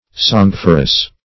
Search Result for " sanguiferous" : The Collaborative International Dictionary of English v.0.48: Sanguiferous \San*guif"er*ous\, a. [L. sanguis blood + -ferous.]
sanguiferous.mp3